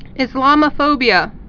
(ĭs-lämə-fōbē-ə)